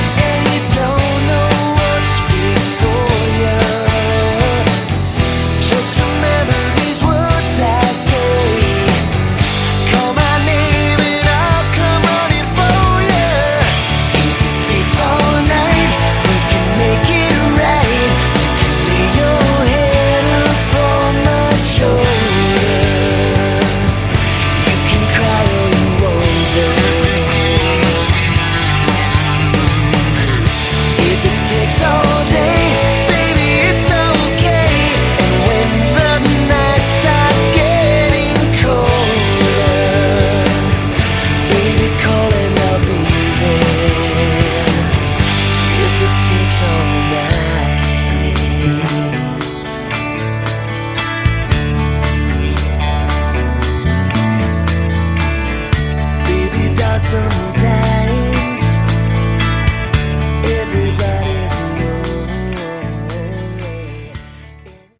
Category: Melodic Rock/Aor
lead vocals
guitars, bass, vocals
drums, keys, vocals